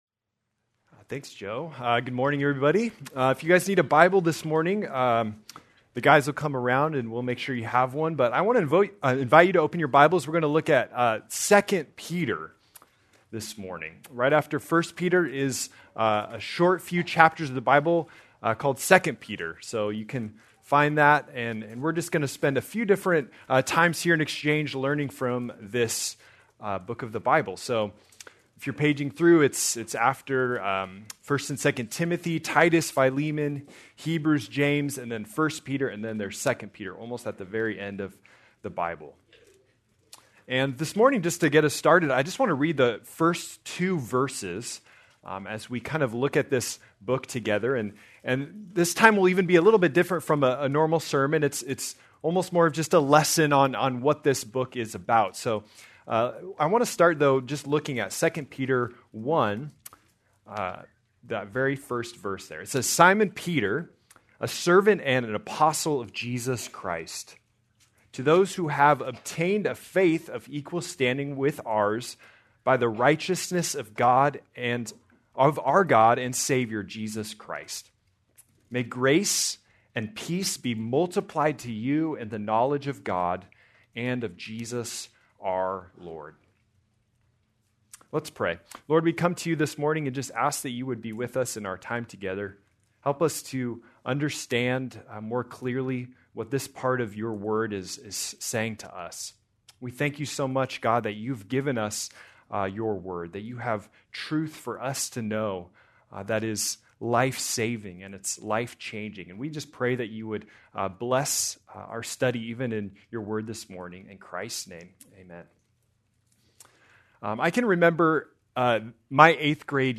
March 1, 2026 -Sermon